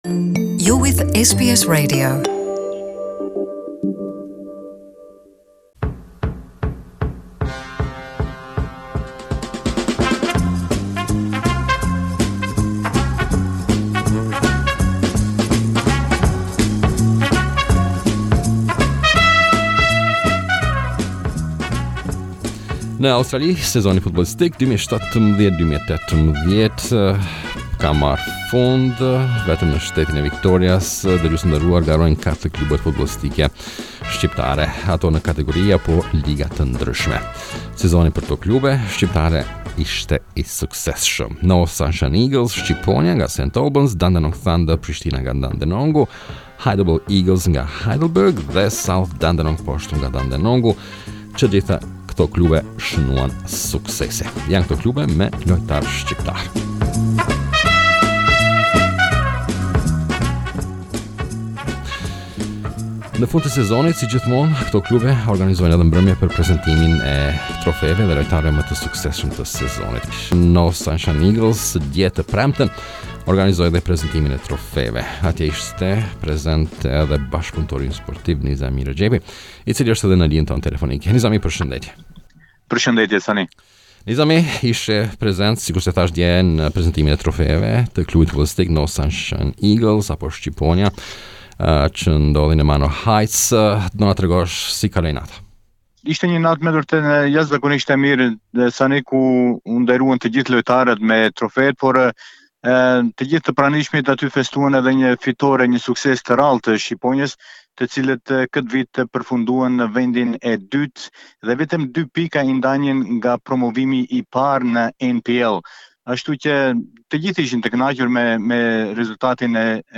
To learn more we got an interview